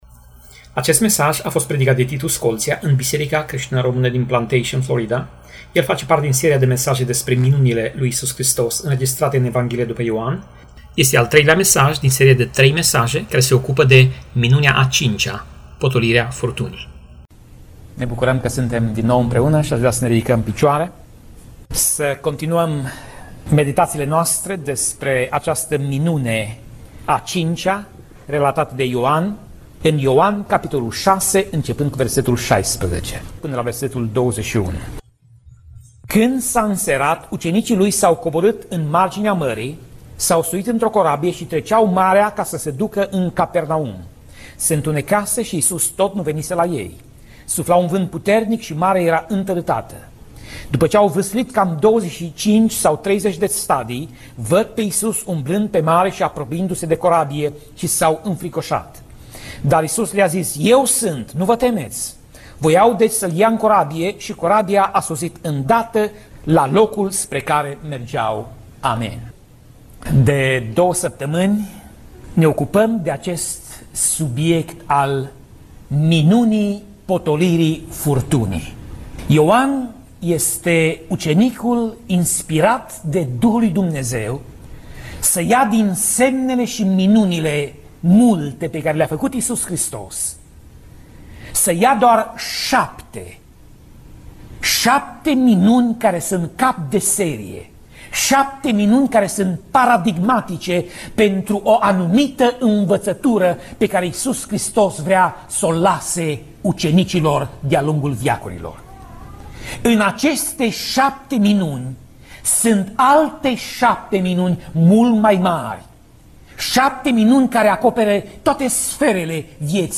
Pasaj Biblie: Ioan 6:16 - Ioan 6:21 Tip Mesaj: Predica